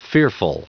Prononciation du mot fearful en anglais (fichier audio)
Prononciation du mot : fearful
fearful.wav